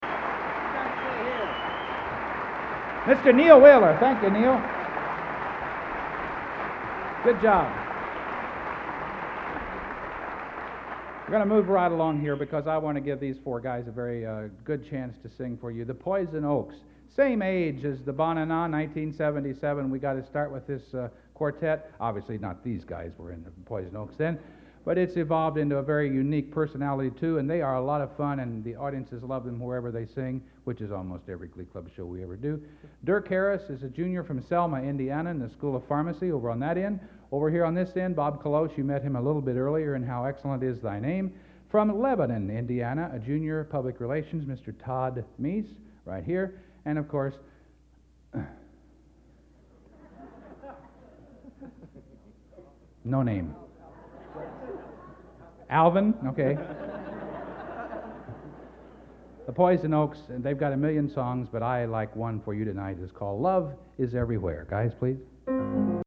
Collection: End of Season, 1986
Location: West Lafayette, Indiana
Genre: | Type: Director intros, emceeing |End of Season